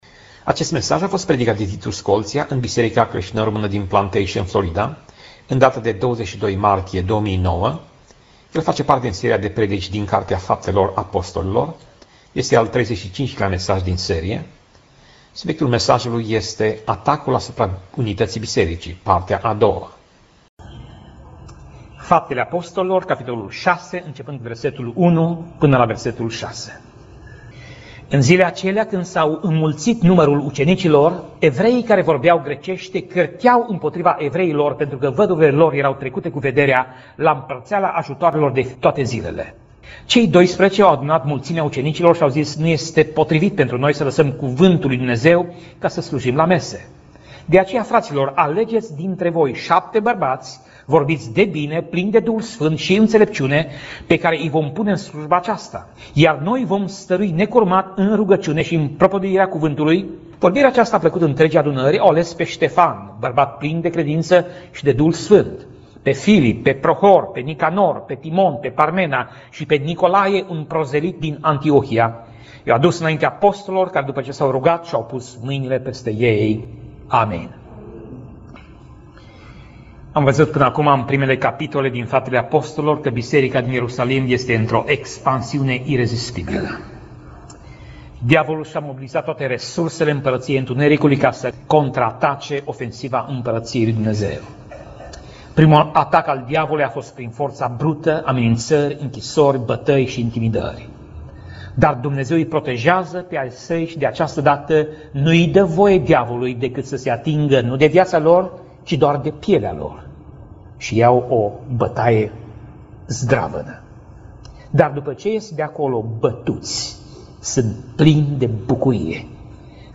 Pasaj Biblie: Faptele Apostolilor 6:1 - Faptele Apostolilor 6:7 Tip Mesaj: Predica